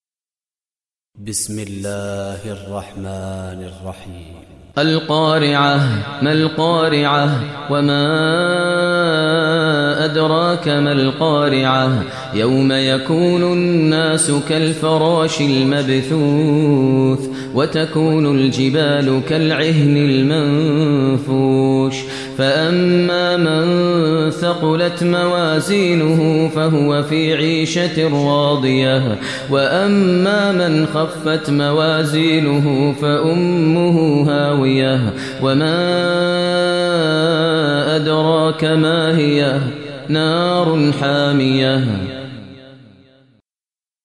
Surah Qariah Recitation by Maher Mueaqly
Surah Qariah, listen online mp3 tilawat / recitation in Arabic in the voice of Imam e Kaaba Sheikh Maher al Mueaqly.